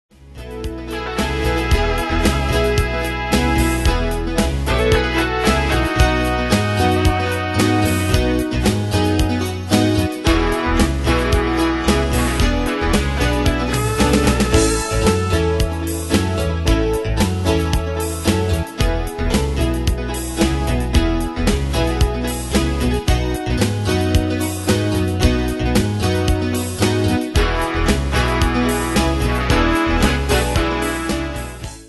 Style: Country Année/Year: 1976 Tempo: 112 Durée/Time: 3.11
Danse/Dance: PopRock Cat Id.
Pro Backing Tracks